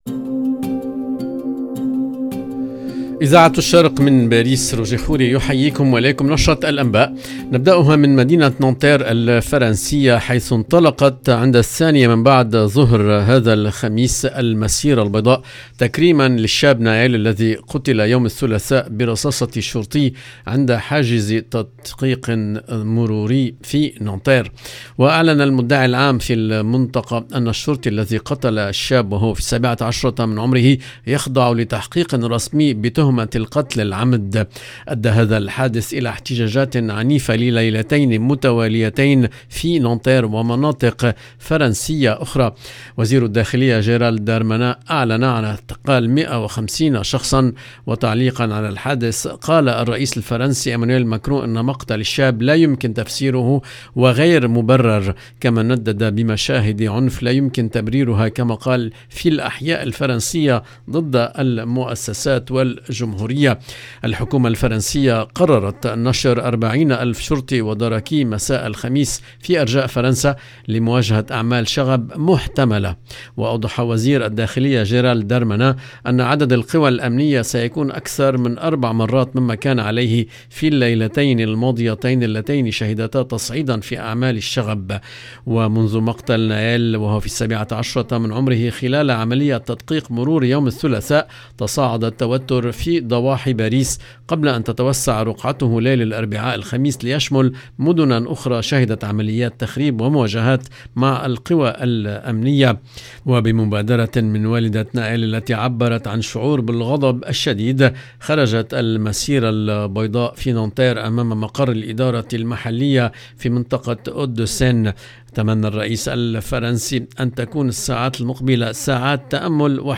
LE JOURNAL EN LANGUE ARABE DU SOIR DU 29/06/23